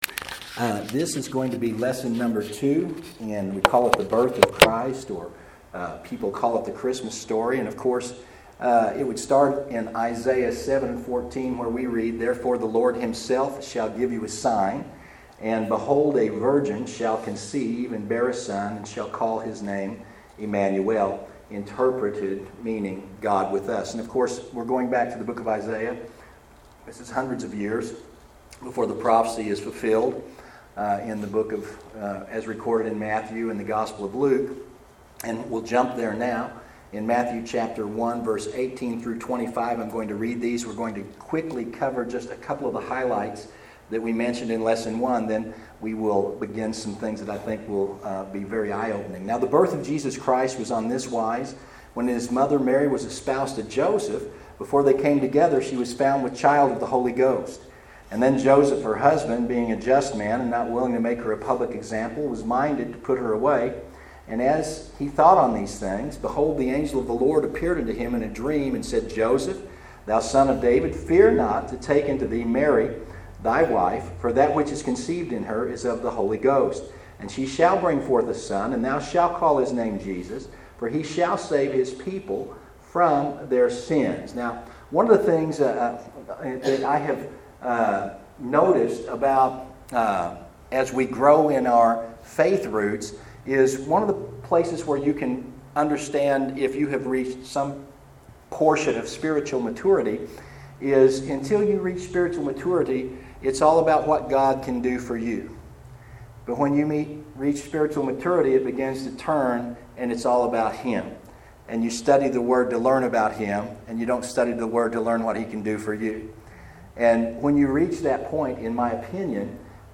What astrological anomalies occurred to cause a star to appear over Bethlehem? These questions are answered in this second of five lessons on “The Birth of Christ.”